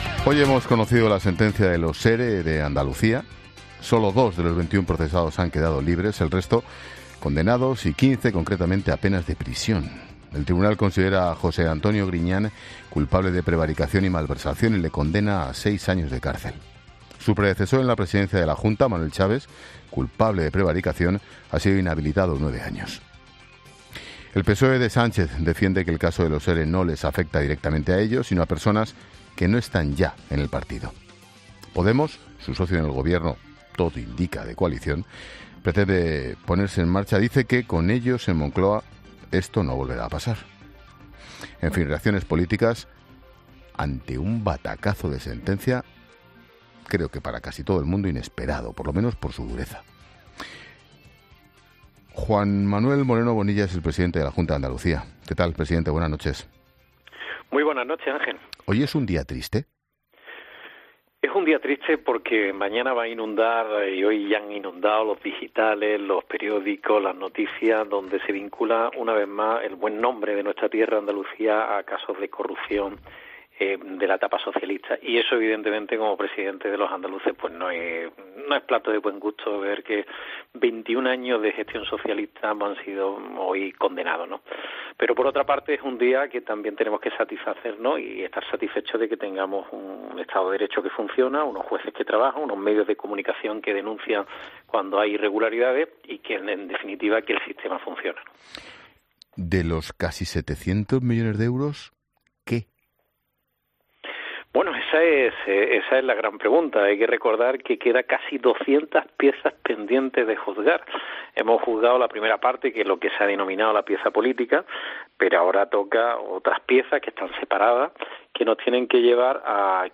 “ Hay que ser consecuente con lo que se dice y se hace ”, asegura Moreno Así lo ha expresado el presidente de la comunidad autónoma en La Linterna de COPE , donde ha dejado claro que cree que el resultado de las elecciones del pasado 10 de noviembre “ hubiera sido muy diferente ” si se hubiera conocido la sentencia de los ERE antes del día de los comicios generales.